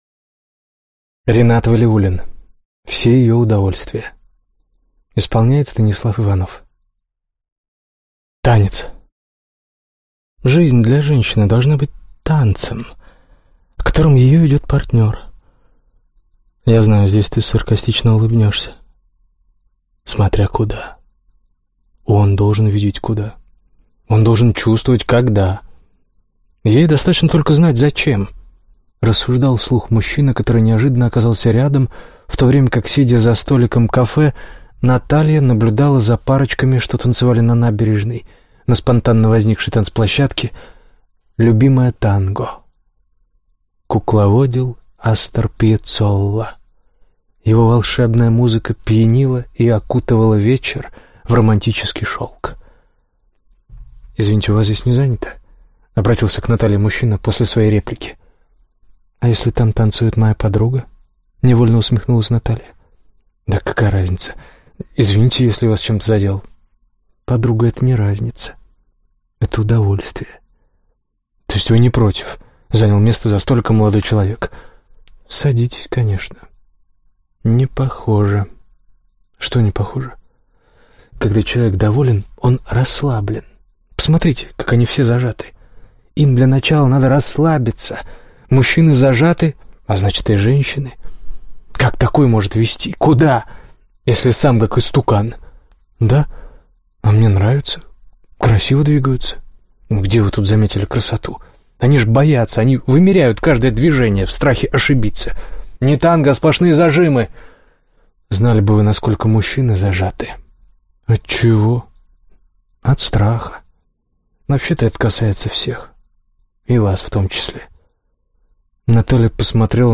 Аудиокнига Все ее удовольствия | Библиотека аудиокниг